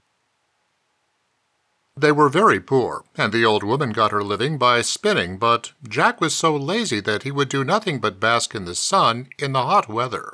That’s Tone, Noise Reduction, DeEssing and Mastering.
It passes ACX Check and sounds reasonable, but I had to do way too much work to get it there.